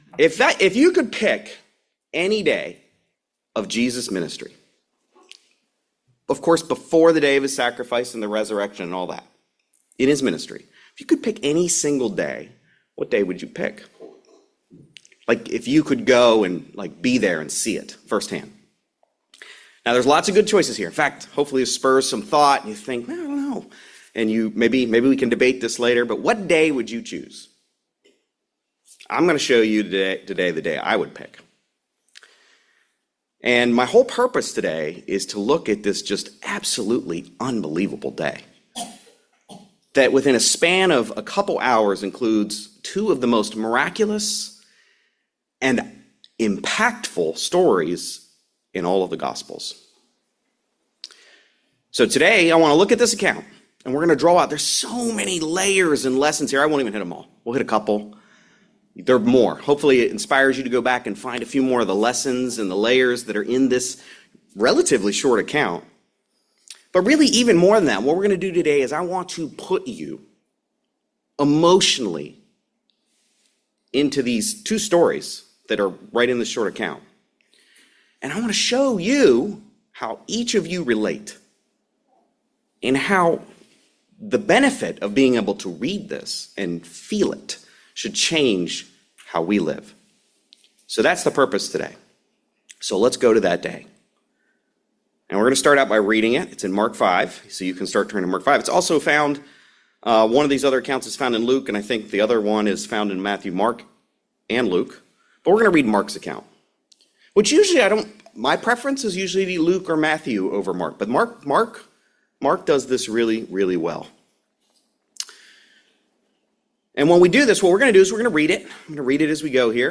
Sermons
Given in Raleigh, NC